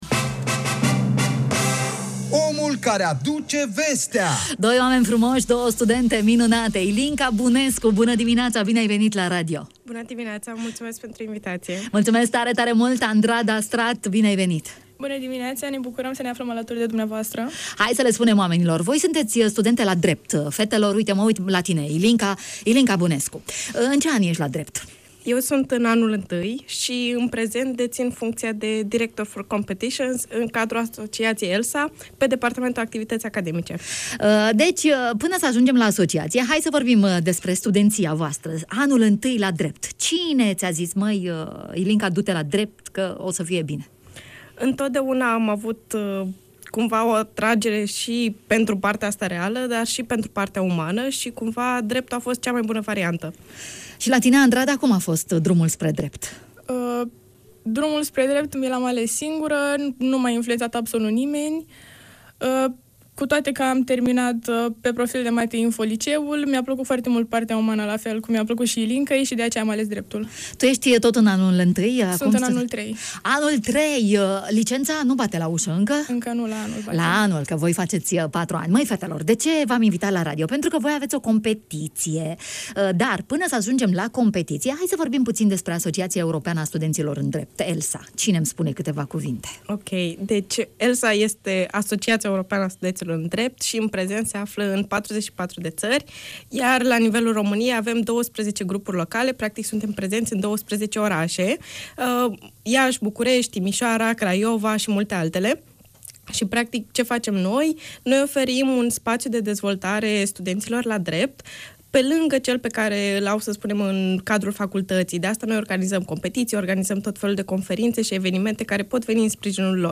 la microfoanele Radio România Iași